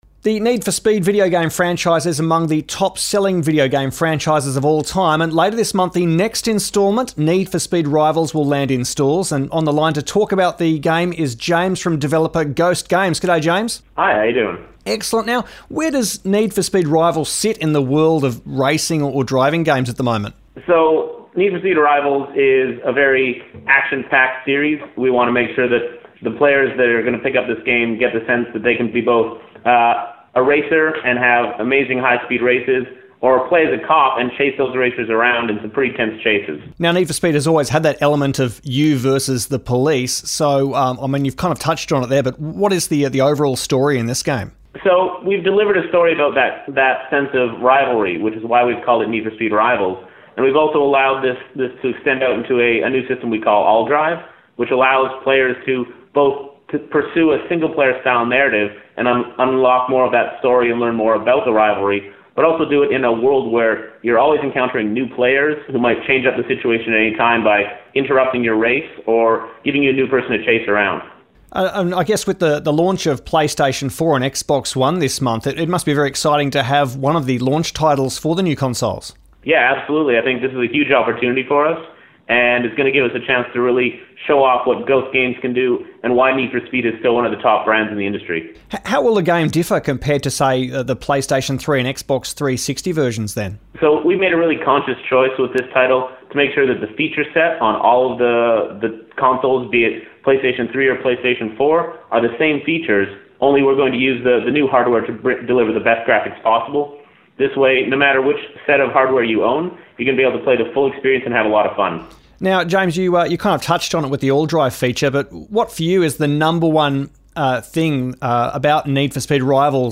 Need for Speed: Rivals – Interview with the developer